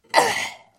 呻吟声、呻吟声和战斗呐喊声 " 00922人被击中7人
描述：人的战斗呻吟声由AKG C414TLII
Tag: 呻吟声 人就播放